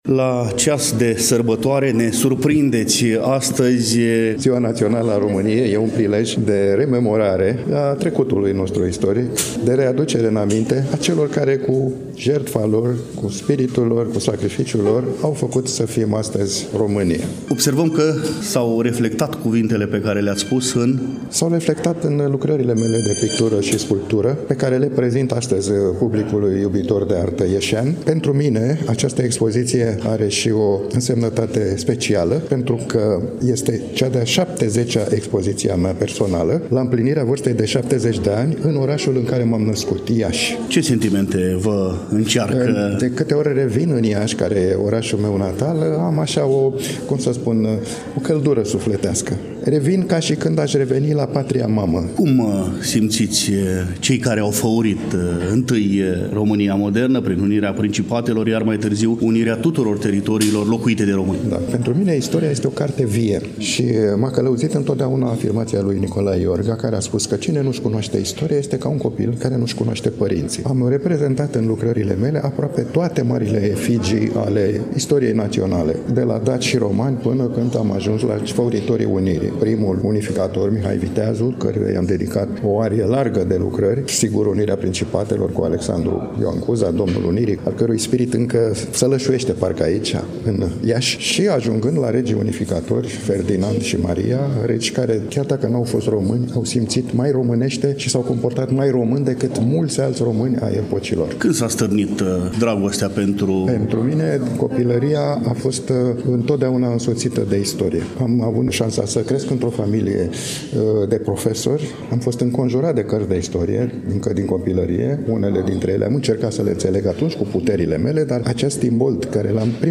Manifestarea a avut loc în ziua de vineri, 29 noiembrie 2024, începând cu ora 11 în incinta Palatul Braunstein din târgul Iașului.